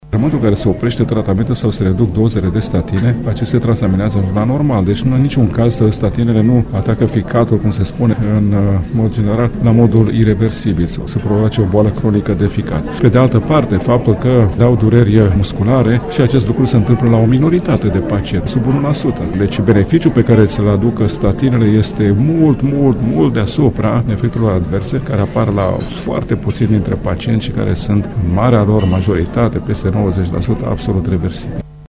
cu prilejul celei de-a XVI-a ediţii a Conferinţei Anuale de Aterotromboză